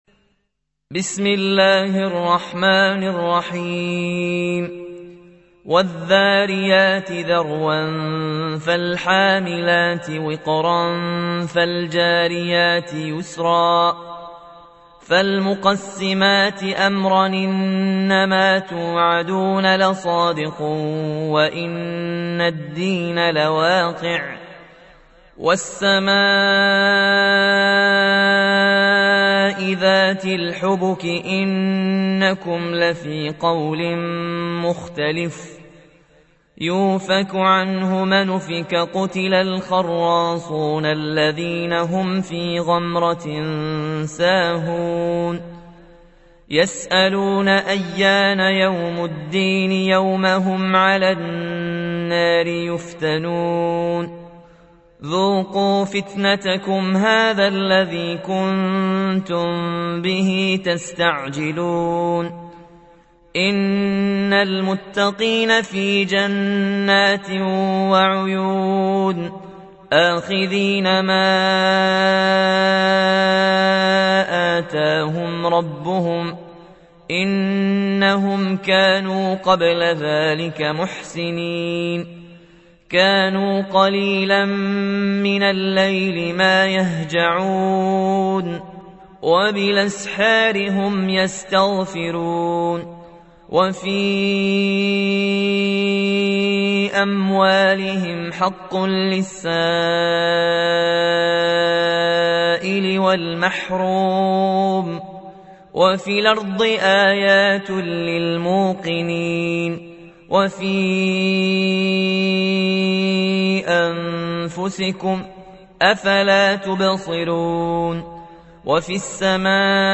تحميل : 51. سورة الذاريات / القارئ ياسين الجزائري / القرآن الكريم / موقع يا حسين